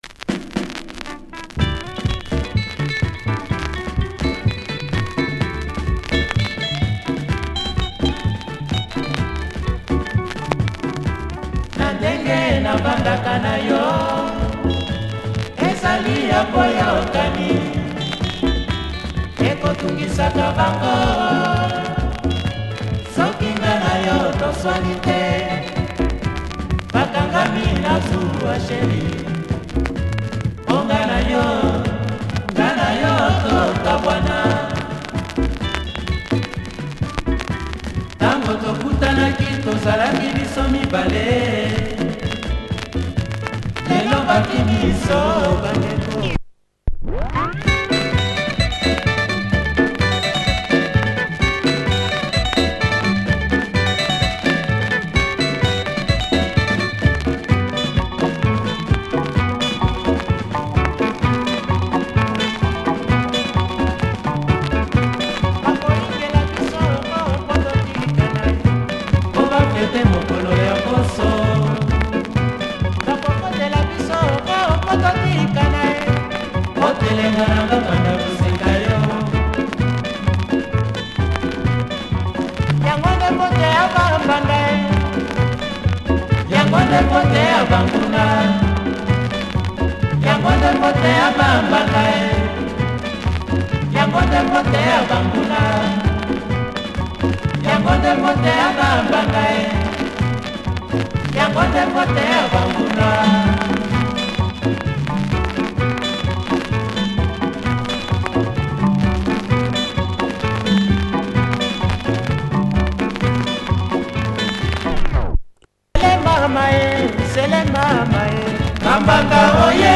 Prominent guitar play and a well crafted track
Some dirt in the grooves that can be cleaned!